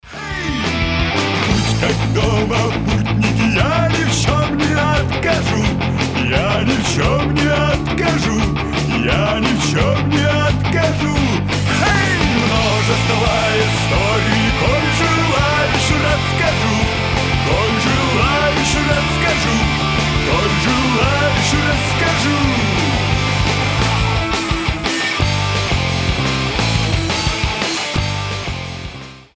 Рок рингтоны
Панк-рок , Фолк-рок